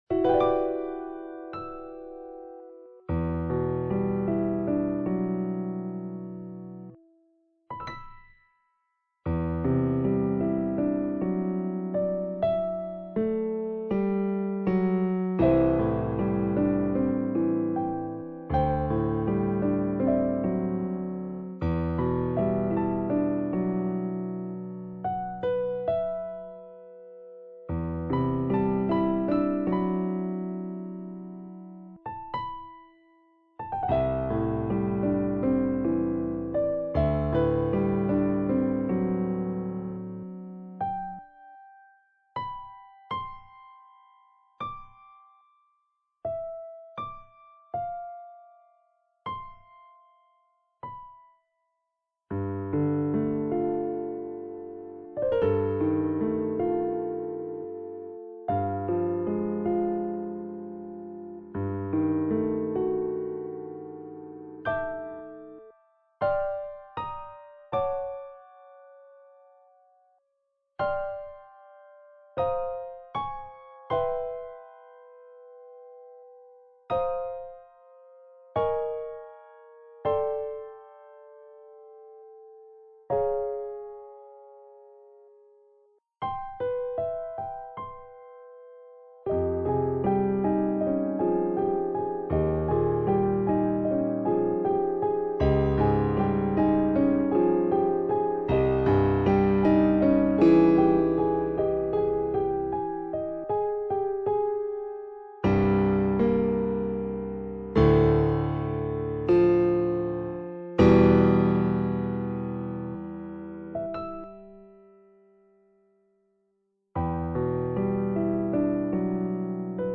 Video games music for the piano